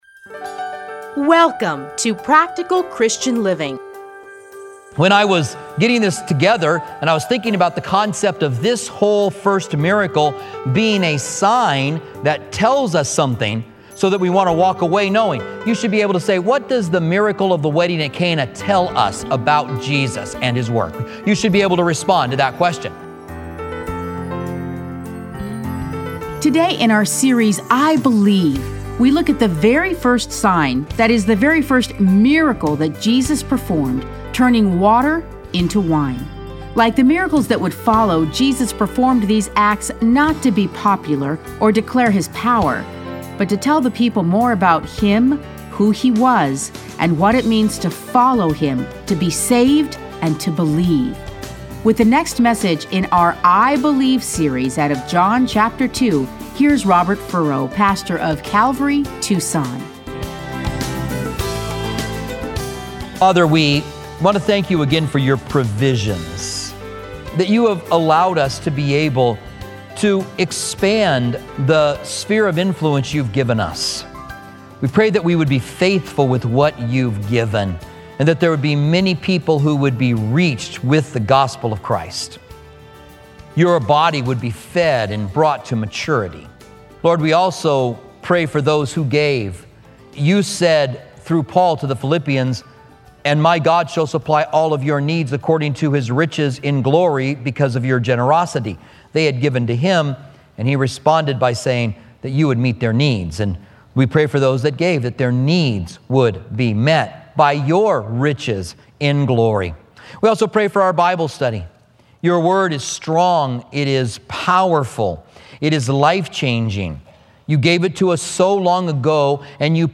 Listen to a teaching from John 2:1-12.